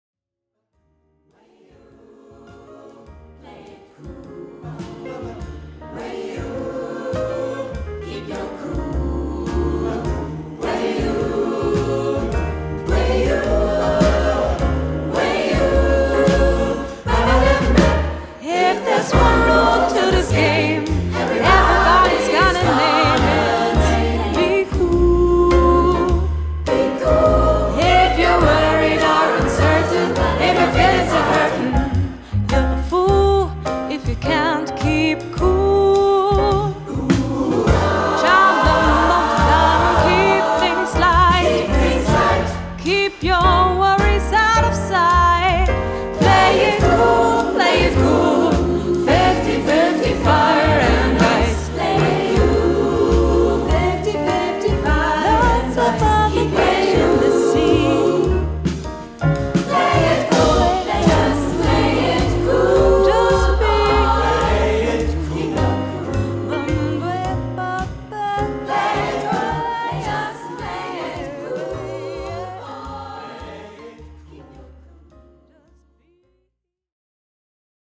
• Dem Tonstudio Tessmar in Hannover